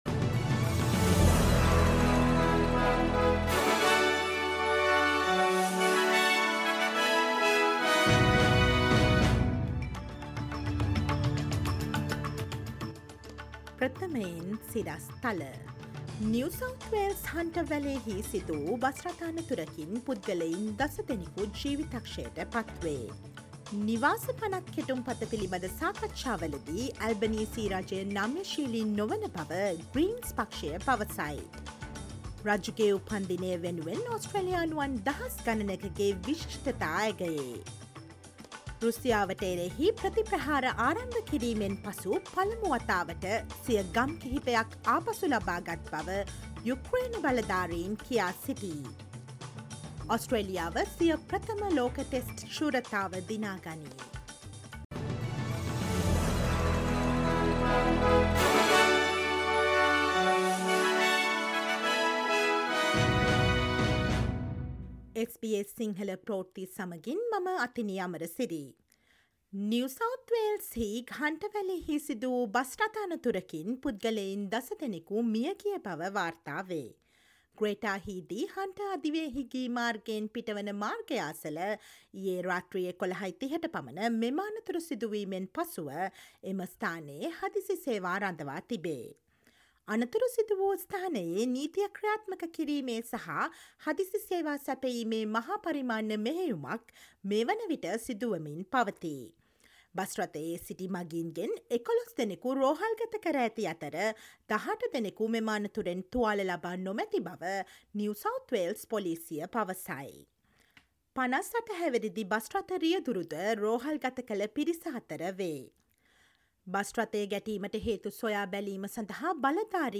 Listen to the latest news from Australia, across the globe, and the latest sports news on SBS Sinhala radio on Monday, 12 June 2023.